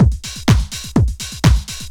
Event Beat 2_125.wav